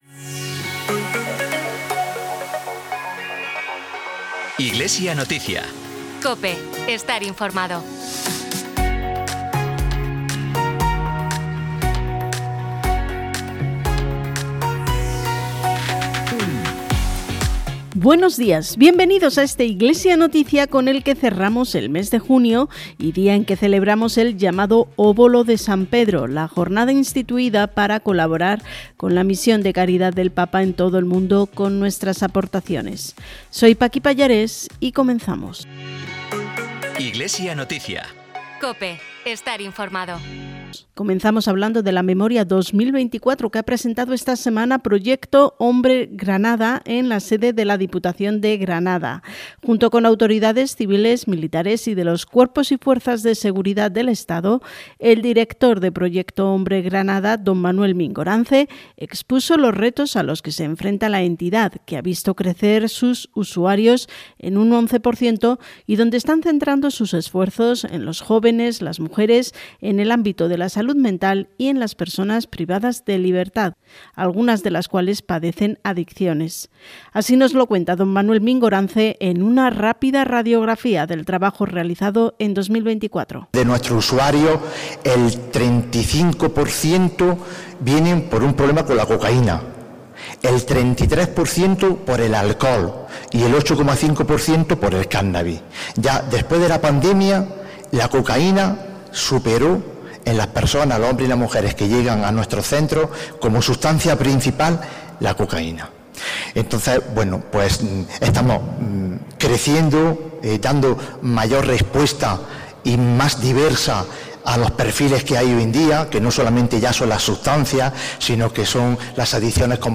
Programa emitido en COPE Granada y COPE Motril el 29 de junio.